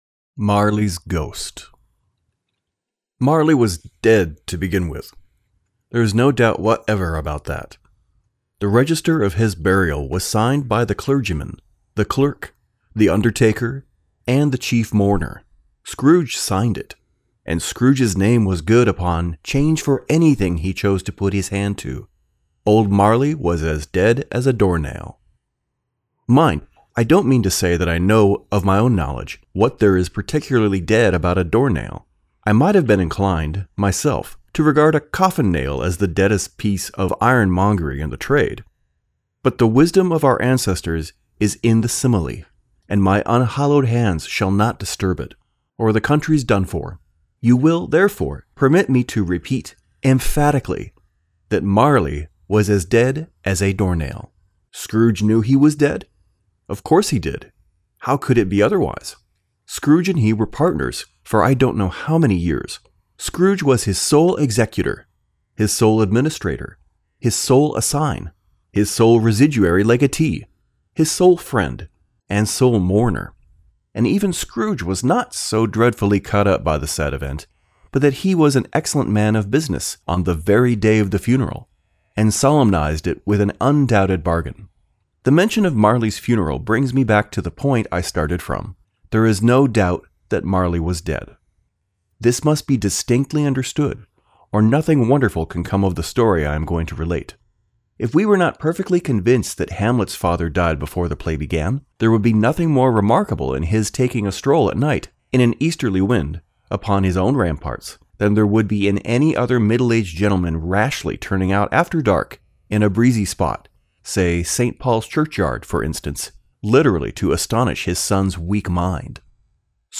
American Voice Over Talent
Adult (30-50) | Older Sound (50+)
Our voice over talent record in their professional studios, so you save money!
0716Audiobook_demo_-_Excerpt_from_A_Christmas_Carol_by_Charles_Dickens.mp3